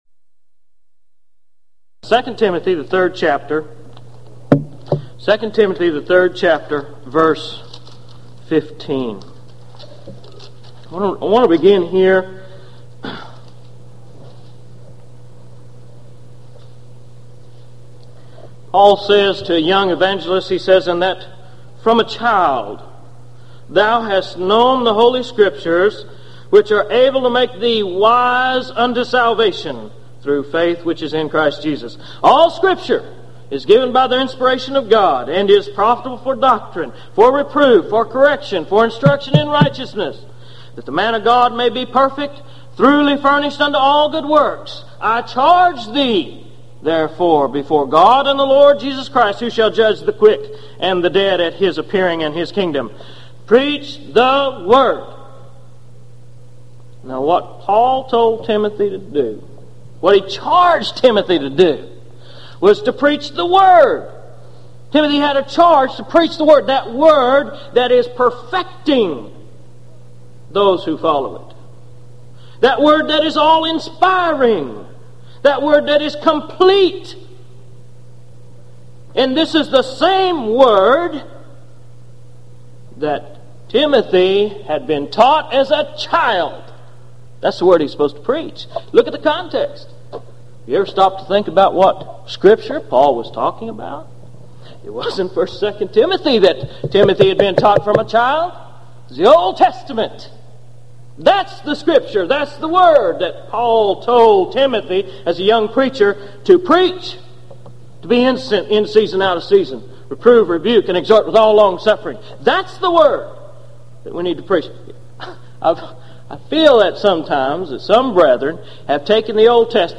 Series: Gulf Coast Lectures Event: 1995 Gulf Coast Lectures Theme/Title: Answering Alleged Contradictions & Problems In The Old Testament